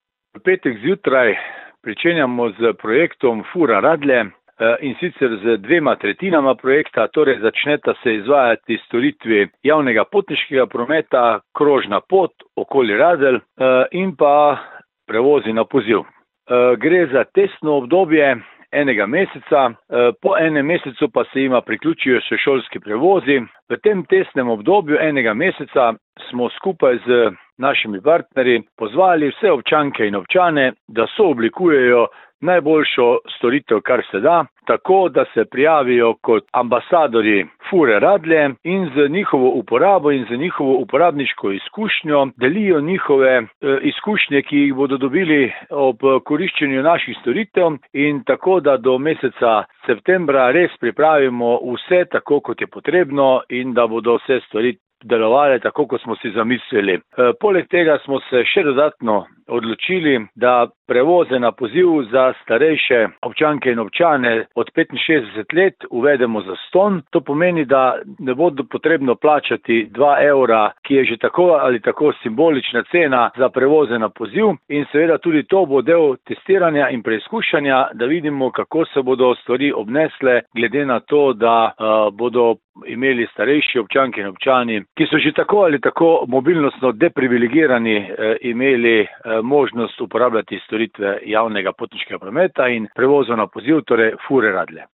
Radeljski župan Alan Bukovnik:
izjava Bukovnik - Fura 1 za splet.mp3